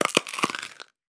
bones.wav